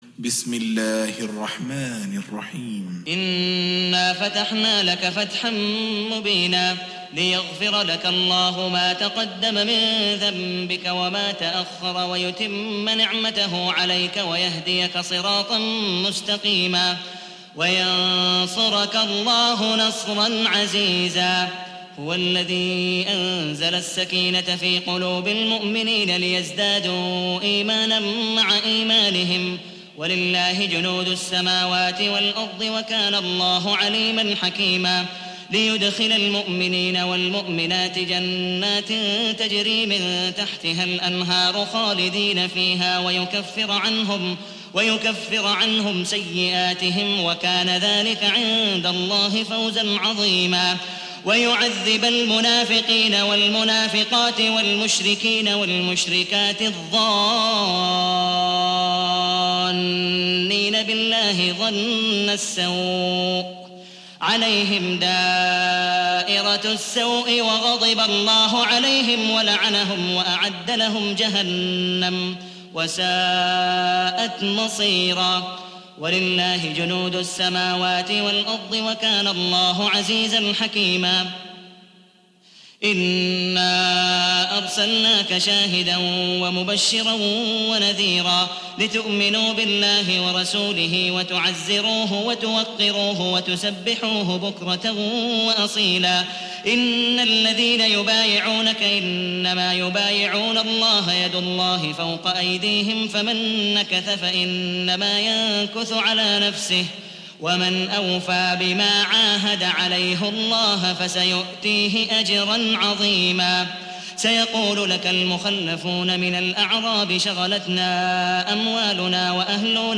Hafs for Assem حفص عن عاصم
Tarteel المرتّلة